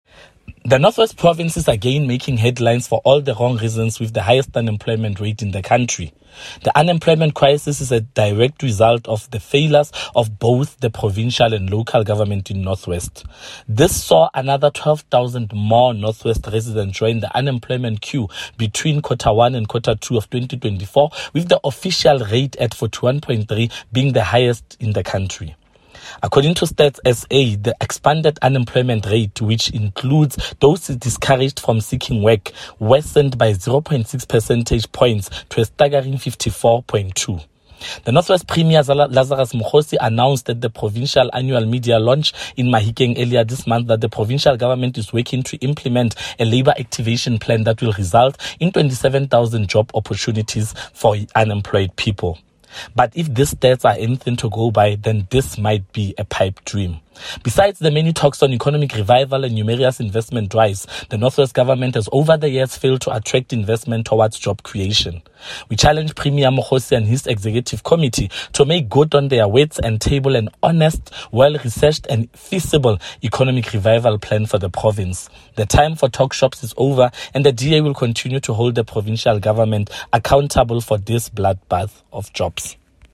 Issued by Freddy Sonakile – DA Caucus Leader in the North West Provincial Legislature
Note to Broadcasters: Please find attached soundbite in
English by Freddy Sonakile